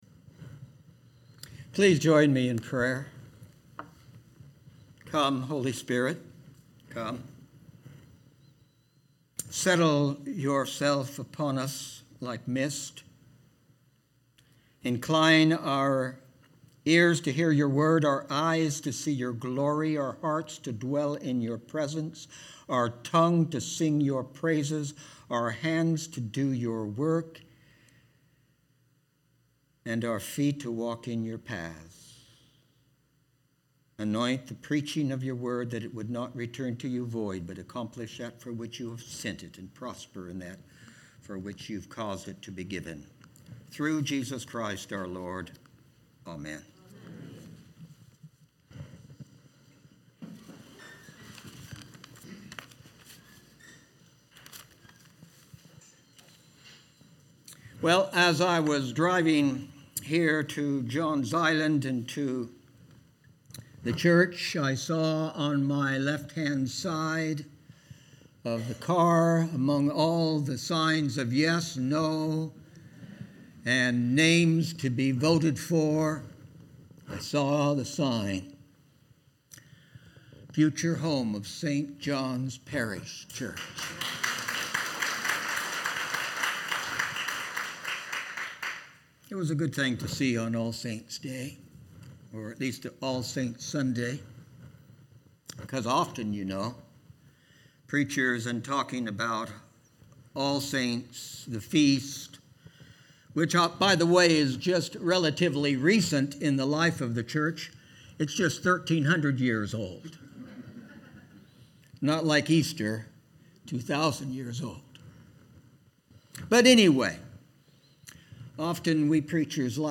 Sermons | St. John's Parish Church
Readings Revelation 7:9-17Psalm 149Ephesians 1:11-23Mathew 5:1-12 Sermon The Rt Rev'd Mark Lawrence